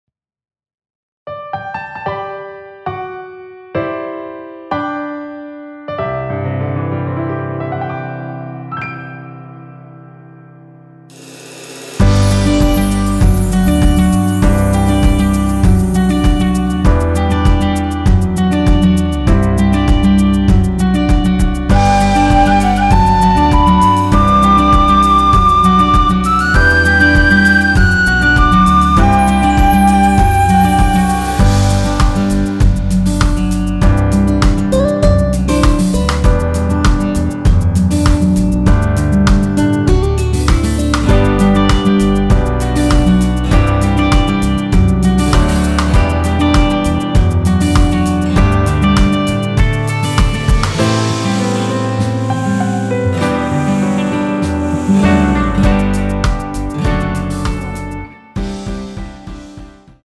원키에서(-1)내린(1절삭제) MR입니다.
◈ 곡명 옆 (-1)은 반음 내림, (+1)은 반음 올림 입니다.
앞부분30초, 뒷부분30초씩 편집해서 올려 드리고 있습니다.